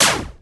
Shoot21.wav